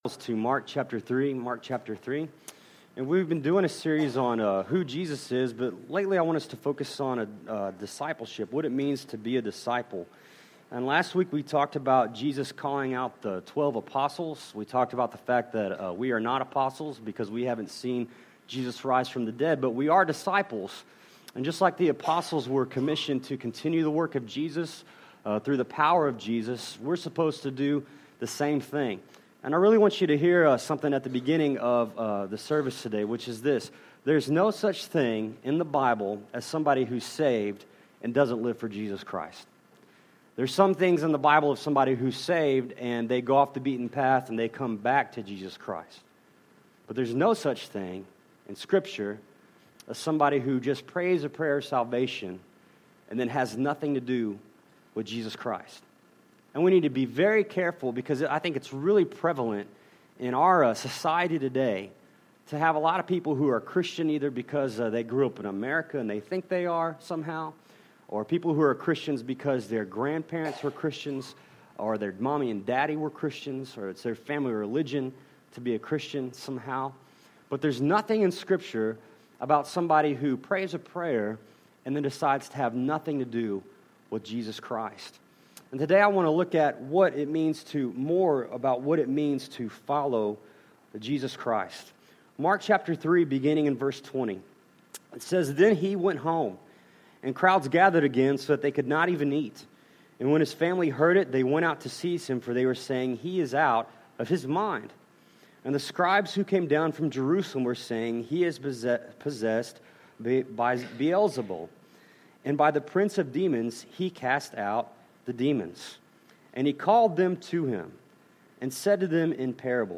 Series: The Gospel of Mark - Who Is Jesus Passage: Mark 3:20-35 Service Type: Sunday Morning